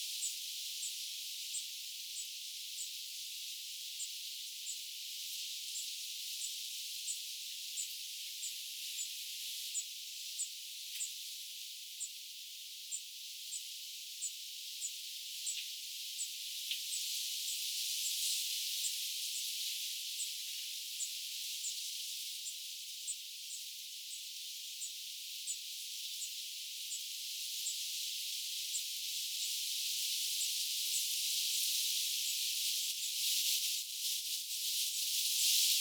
pajusirkun lentopoikanen kerjää
pajusirkun_lentopoikanen_kerjaa.mp3